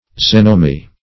Search Result for " xenomi" : The Collaborative International Dictionary of English v.0.48: Xenomi \Xen"o*mi\, prop. n. pl.